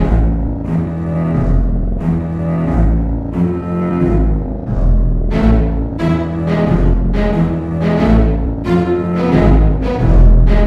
水流 " 水流1
描述：林中小溪的一系列小瀑布中的一个。水在一些岩石之间倾泻而下。
标签： 现场录音 秋季 未处理
声道立体声